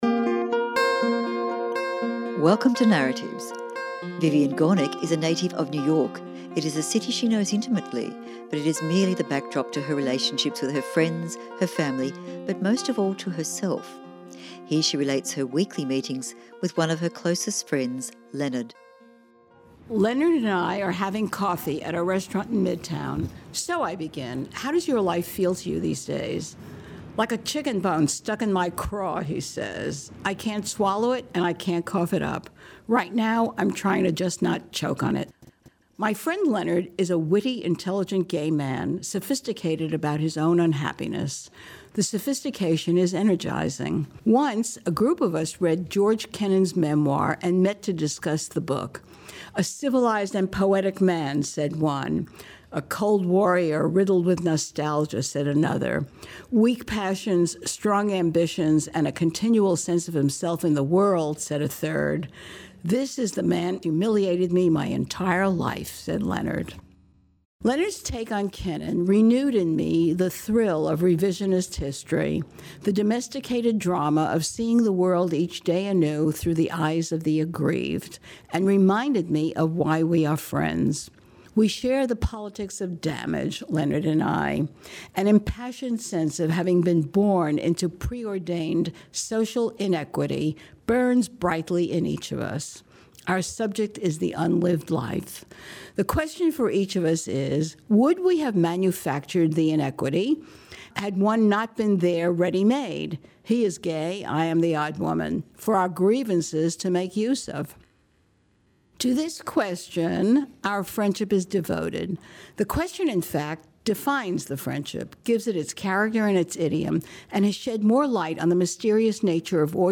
Recorded at Sydney Writers Festival 2016